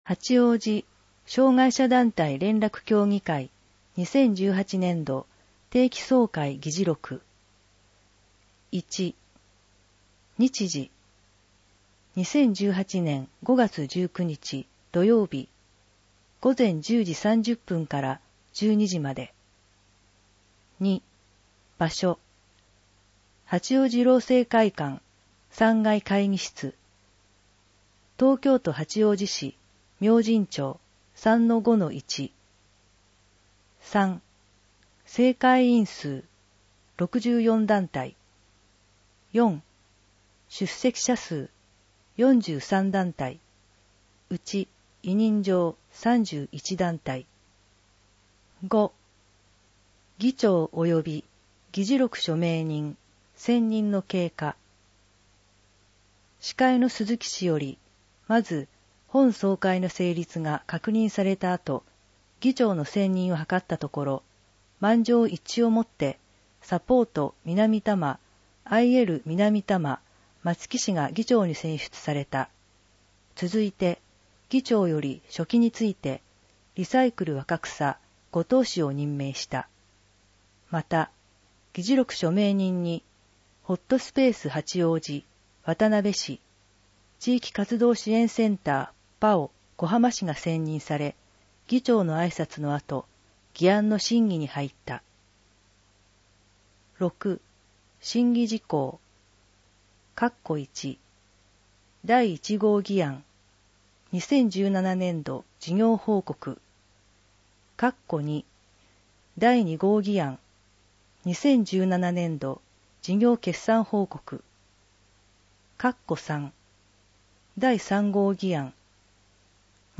2018定期総会議事録をアップします。